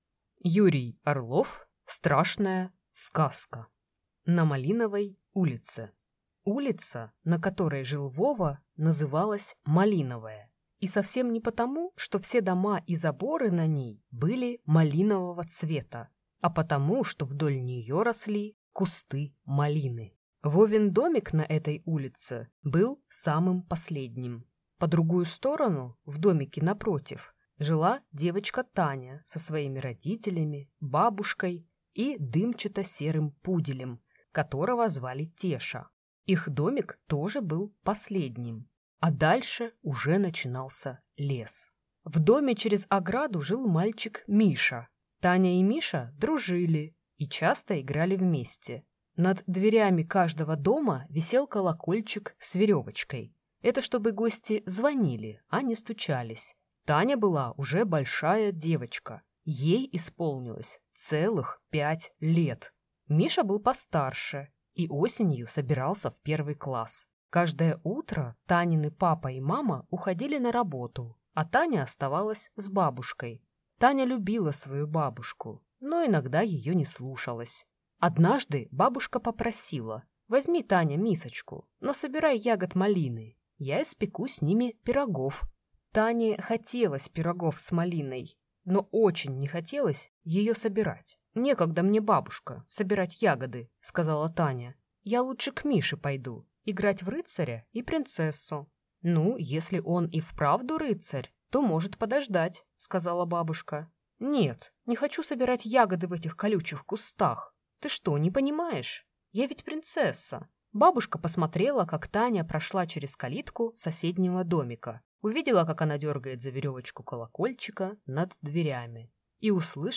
Аудиокнига Страшная сказка | Библиотека аудиокниг